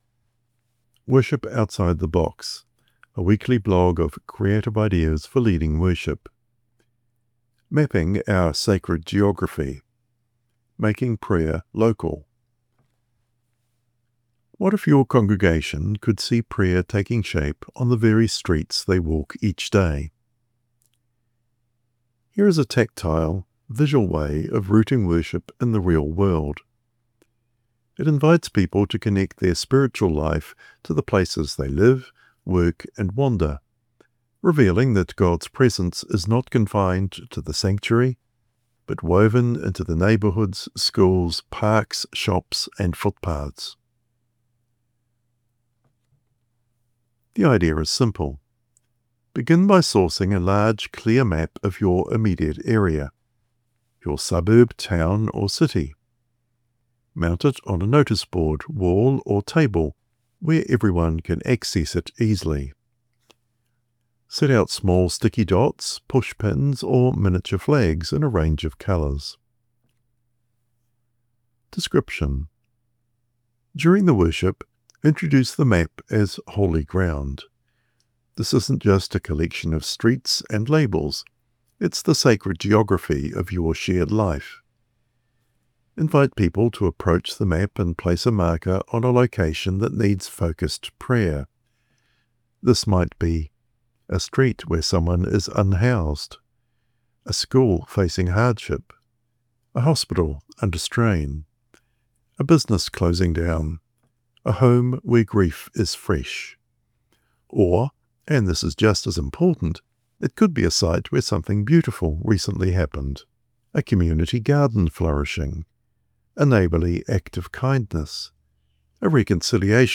Click or audio narration